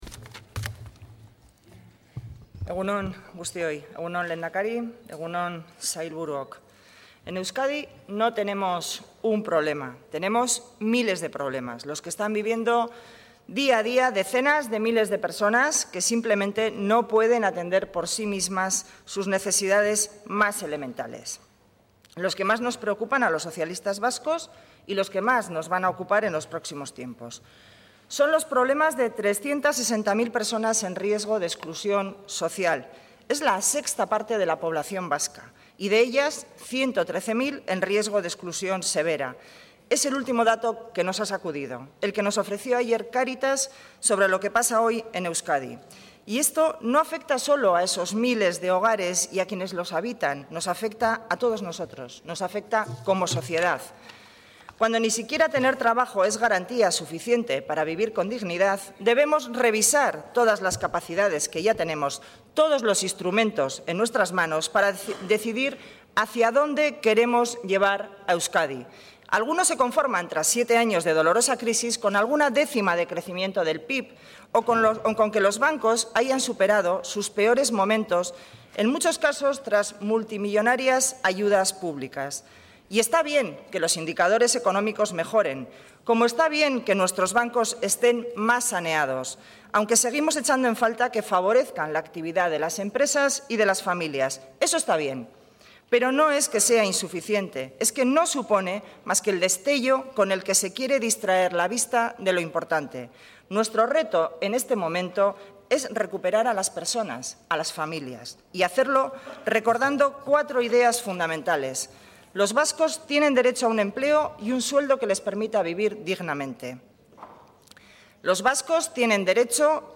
Intervención de Idoia Mendia en el Pleno monográfico sobre paro y pobreza en Euskadi